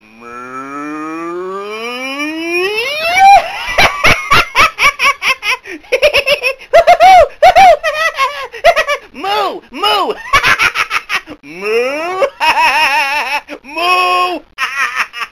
Категория: Рингтоны звуки животных